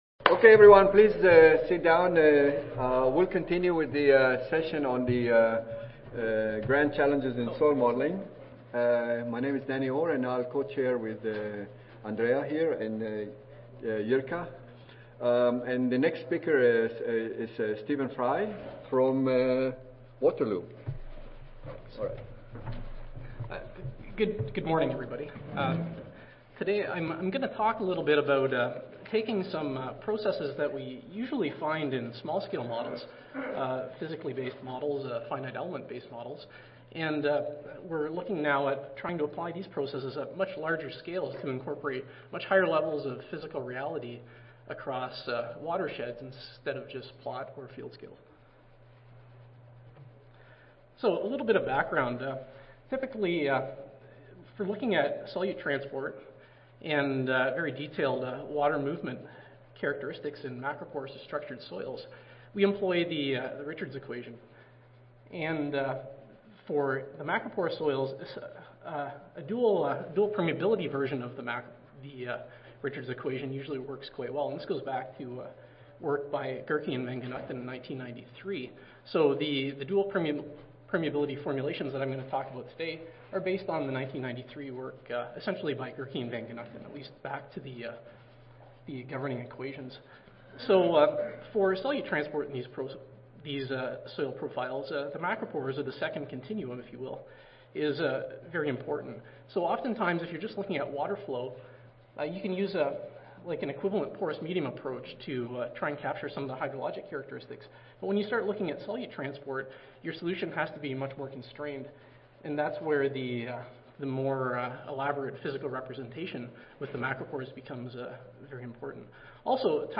University of Waterloo Audio File Recorded Presentation